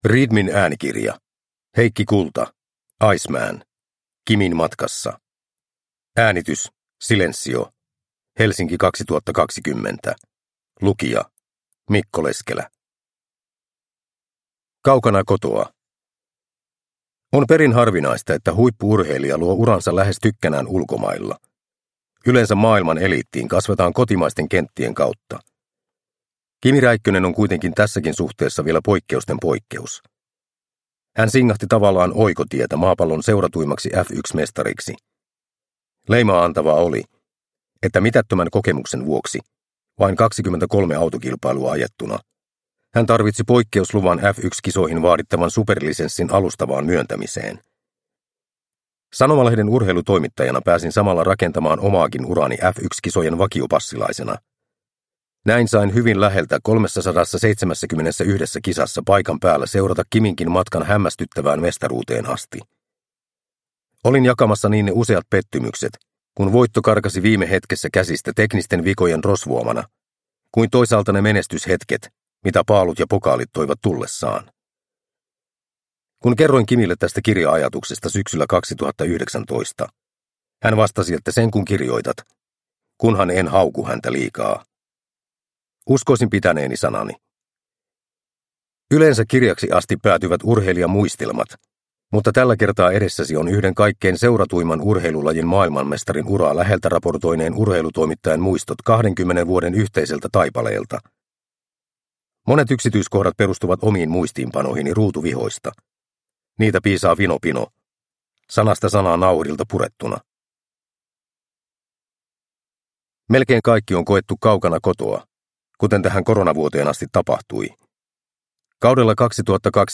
Iceman - Kimin matkassa – Ljudbok